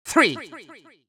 countIn3Farthest.wav